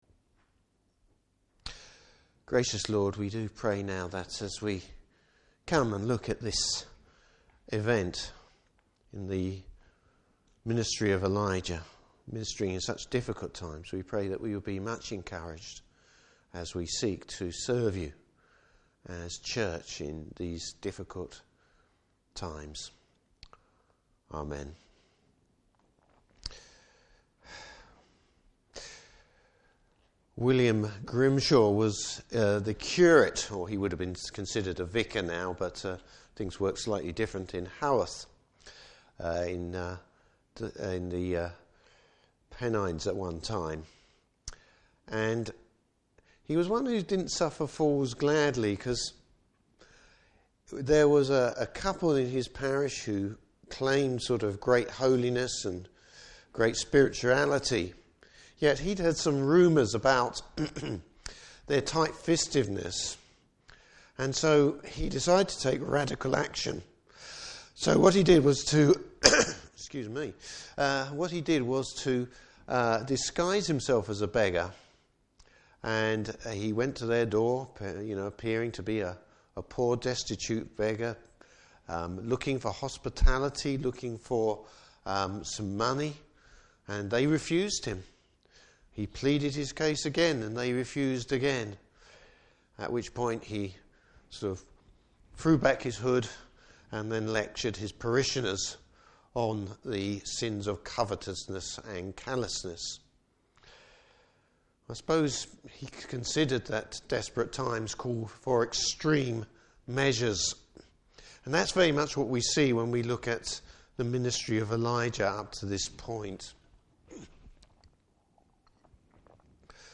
Service Type: Evening Service Bible Text: 1 Kings 18: 16-46.